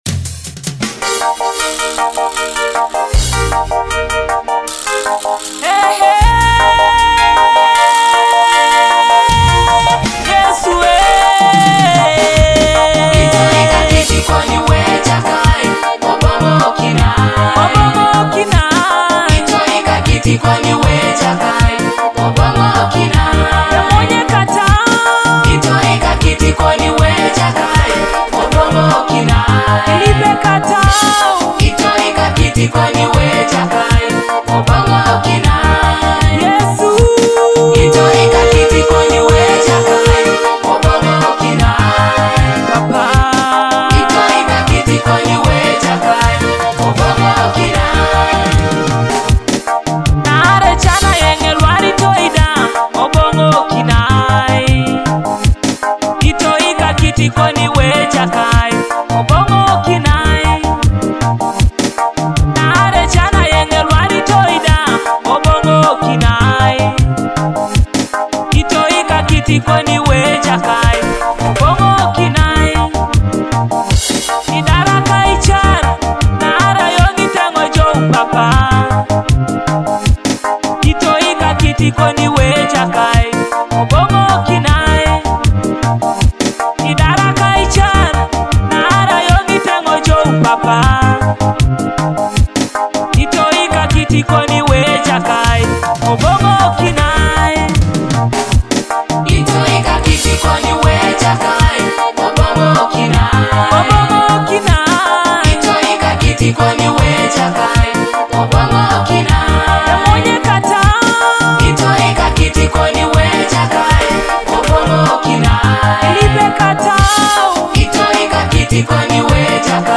a soul-stirring gospel anthem inspiring worship